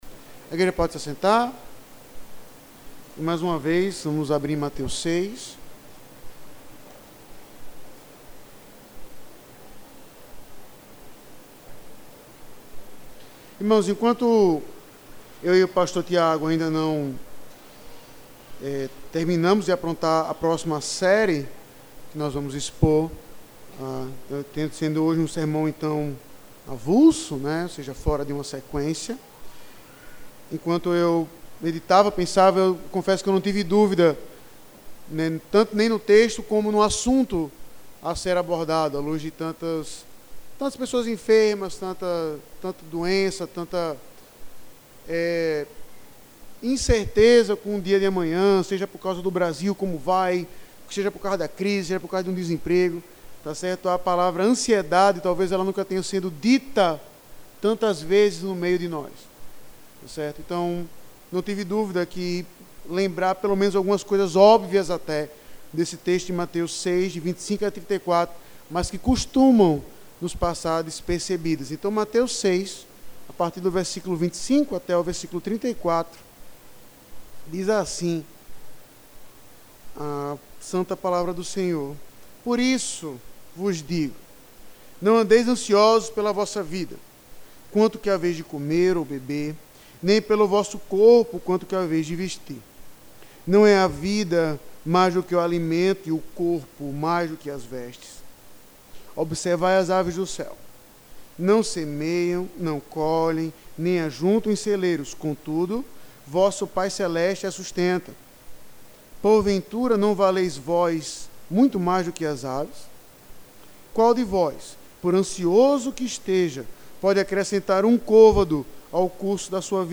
pregacao_18-09.mp3